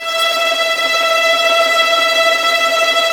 Index of /90_sSampleCDs/Roland LCDP13 String Sections/STR_Violins Trem/STR_Vls Tremolo